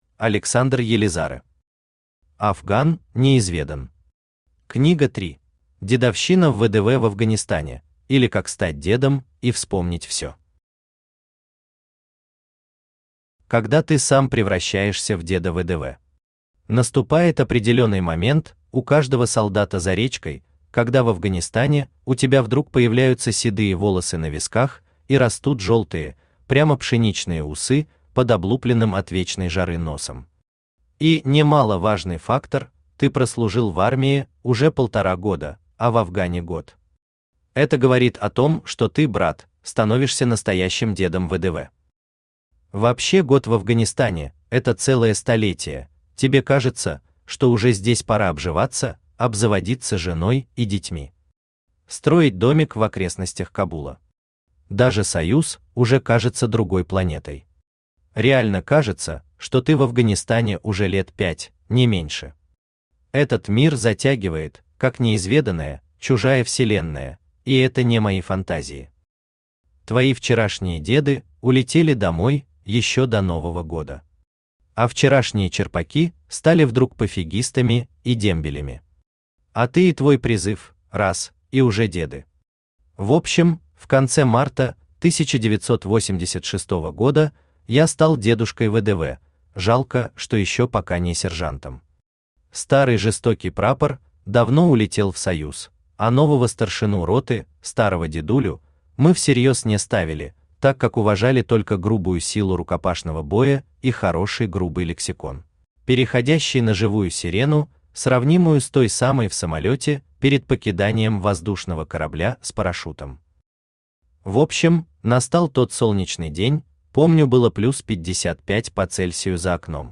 Книга 3 Автор Александр Елизарэ Читает аудиокнигу Авточтец ЛитРес.